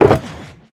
Minecraft Version Minecraft Version 25w18a Latest Release | Latest Snapshot 25w18a / assets / minecraft / sounds / block / shulker_box / open.ogg Compare With Compare With Latest Release | Latest Snapshot